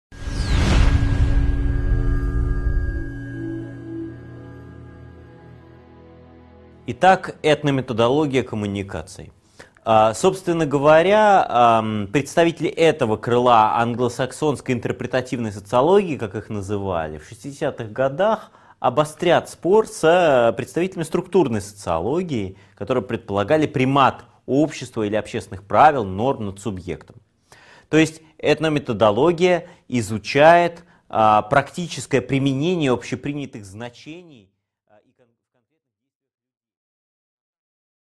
Аудиокнига 10.3 Микросоциальные теории медиа: Этнометодология коммуникаций | Библиотека аудиокниг